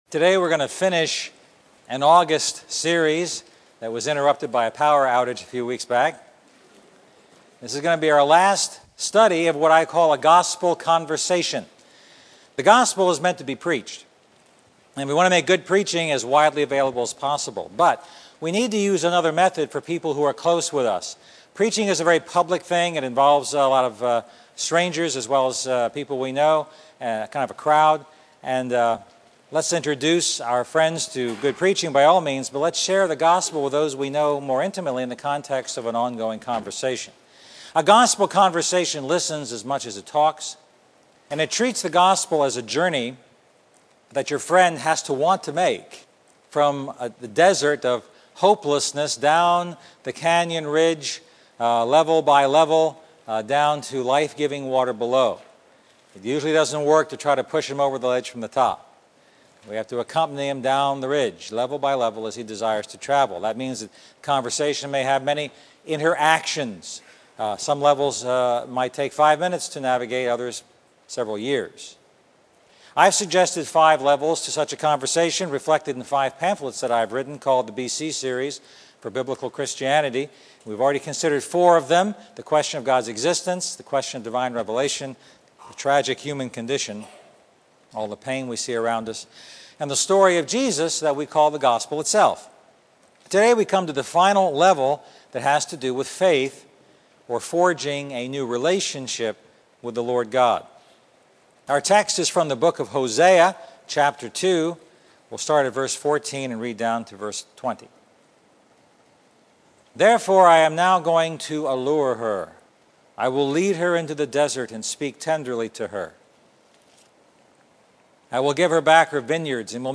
A message from the series "A Gospel Conversation."